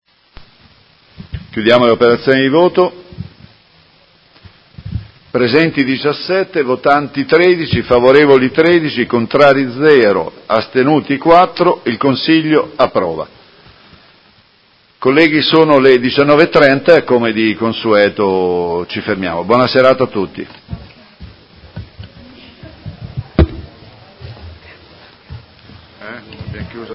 Sedute del 21/03/2019 Come Presidente di turno mette ai voti Ordine del Giorno Prot. Gen. n. 28352 presentato dai Consiglieri Venturelli, Morini, Arletti, Liotti, Fasano, Lenzini, De Lillo, Bortolamasi, Carpentieri, Forghieri, Poggi, Di Padova, Baracchi e Pacchioni (PD) avente per oggetto: Modena città universitaria: il recupero degli alloggi ed edifici dismessi in Centro Storico “Per una città attrattiva fondata sulla cultura, le famiglie e i giovani” – Prima firmataria Consigliera Venturelli: approvato. Chiude i lavori del Consiglio.